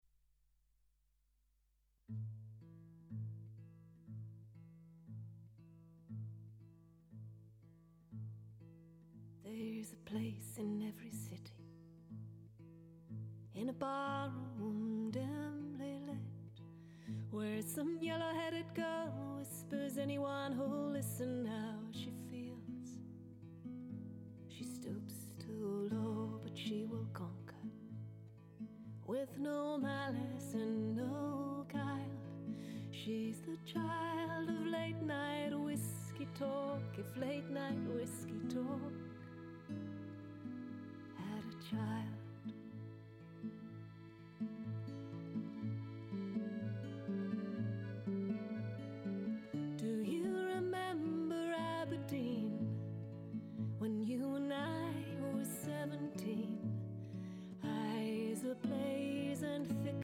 même réglage de compression que celle
avec LA2A
et toujours pas vraiment un bon déeesseur